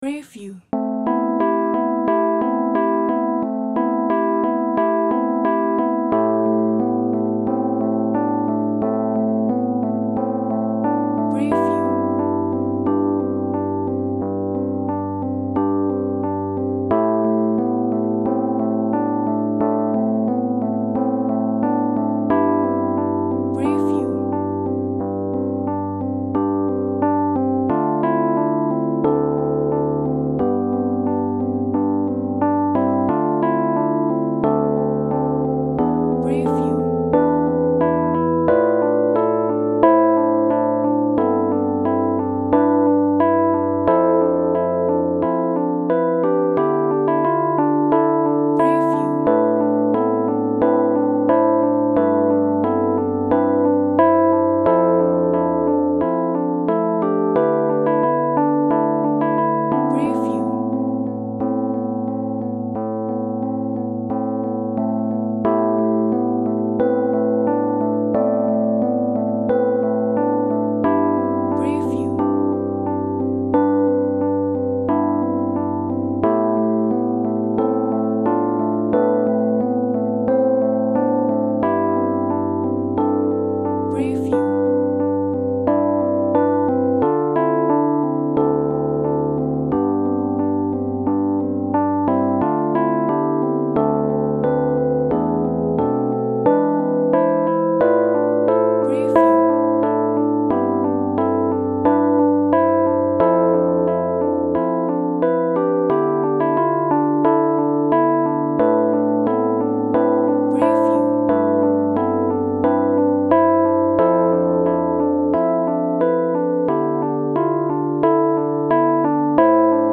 SSATB Жанр: пісня К-сть сторінок
Написане для мішаного 5ти голосого хору з поділеним сопрано.